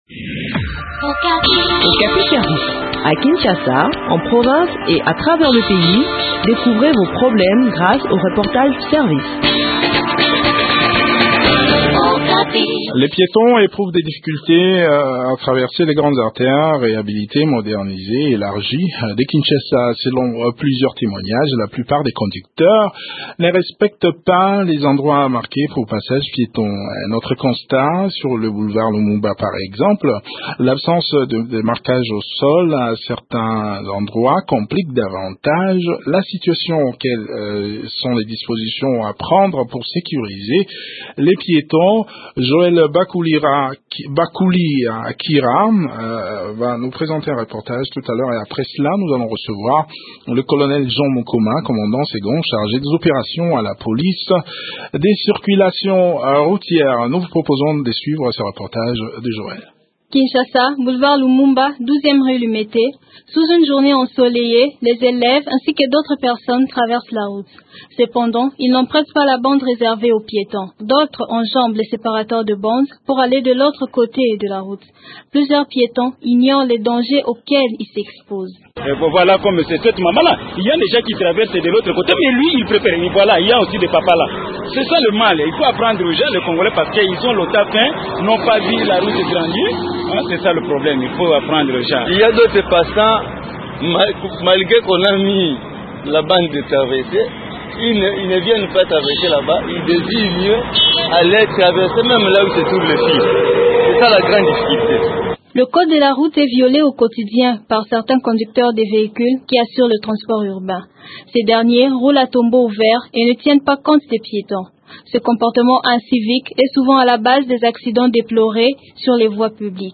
Selon quelques piétons interviewés, certains conducteurs des taxis et taxis-bus ne respectent pas la priorité des passants qui s’engagent sur la bande qui leur est réservée. L’absence du marquage au sol à certains endroits complique davantage la situation.